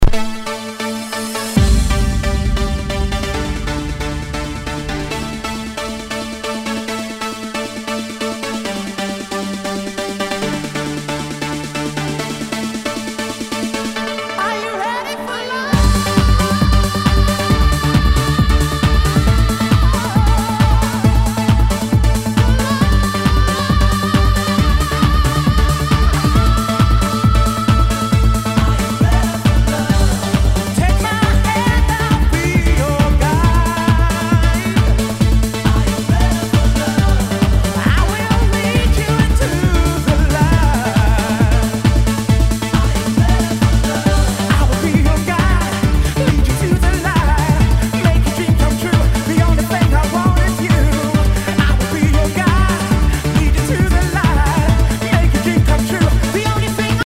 HOUSE/TECHNO/ELECTRO
ナイス！ユーロ・ヴォーカル・ハウス！